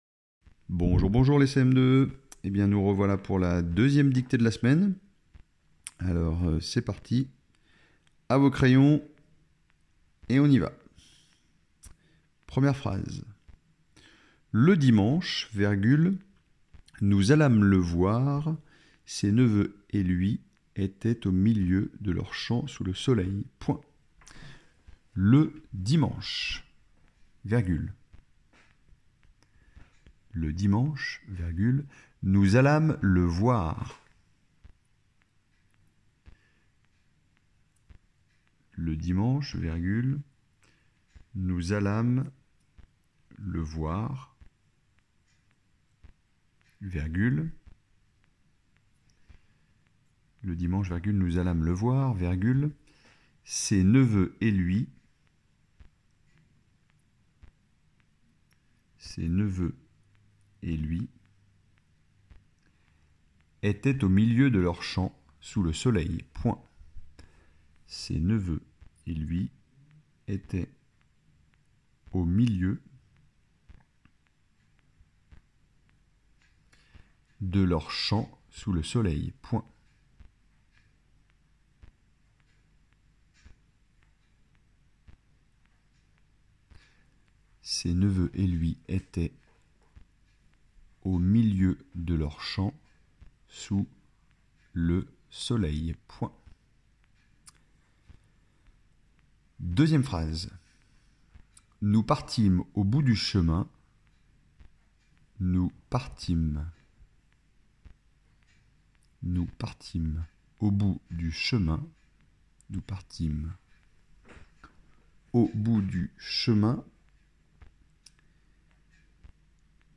-Dictée :